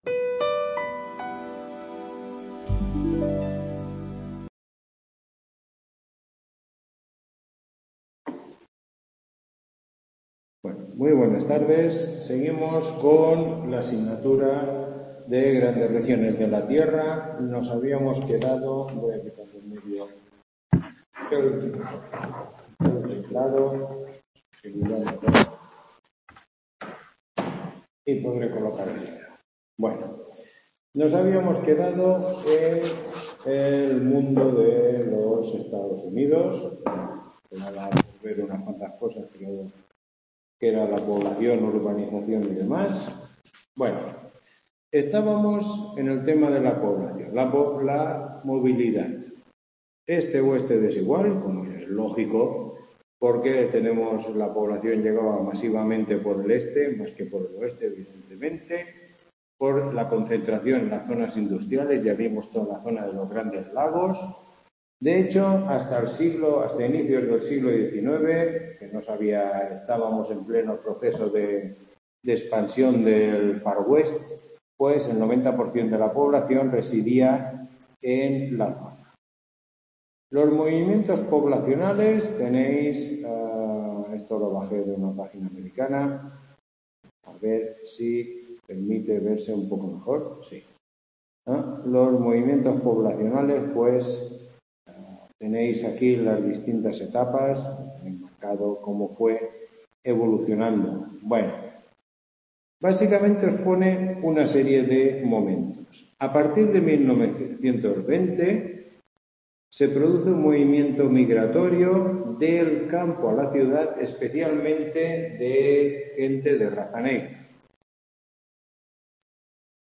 Tutoría 07